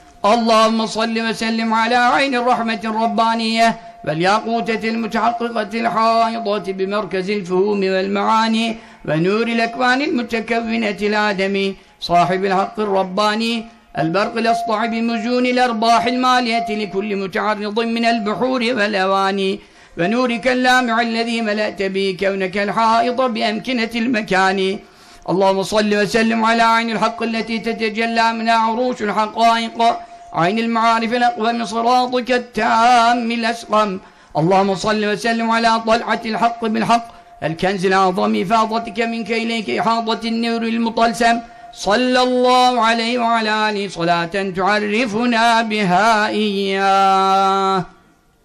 Okunuşu
Cübbeli Ahmet Hoca okuyor